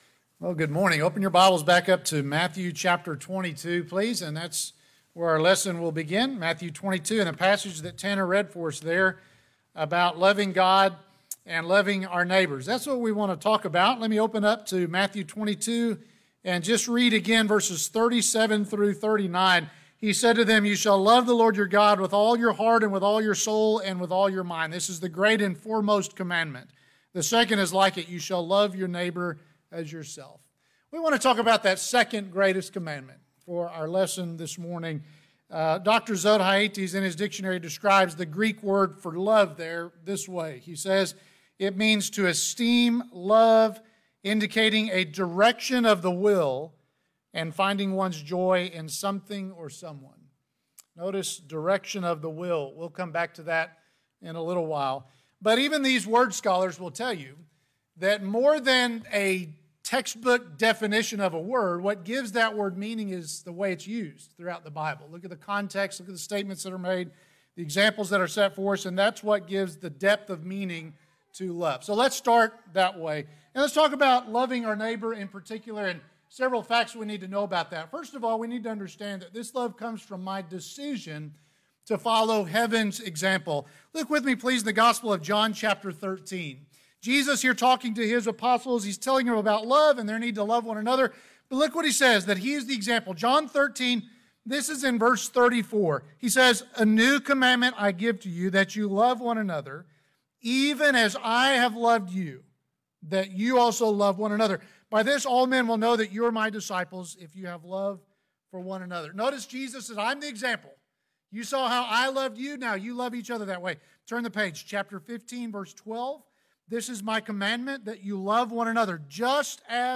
Sermons - Benchley church of Christ